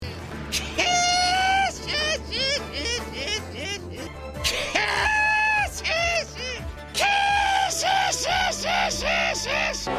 One Piece Gecko Moria Laugh